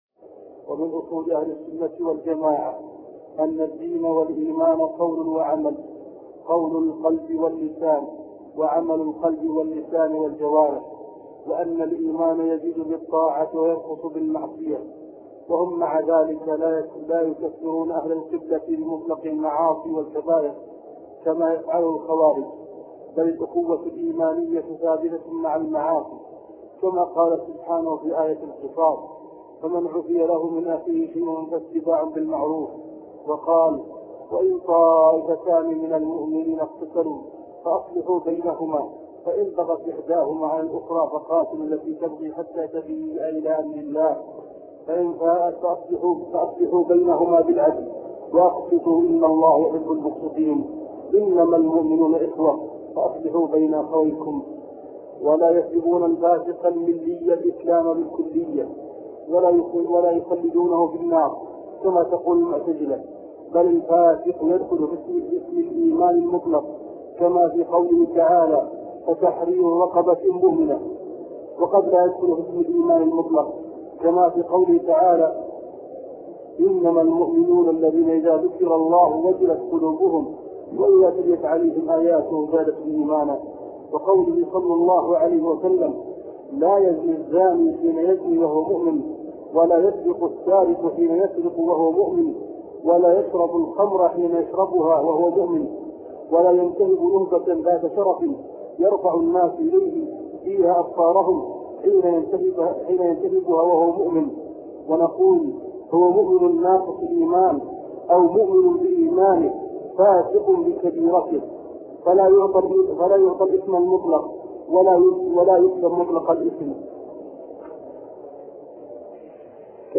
عنوان المادة الدرس (26) شرح العقيدة الواسطية تاريخ التحميل الجمعة 3 فبراير 2023 مـ حجم المادة 20.20 ميجا بايت عدد الزيارات 171 زيارة عدد مرات الحفظ 72 مرة إستماع المادة حفظ المادة اضف تعليقك أرسل لصديق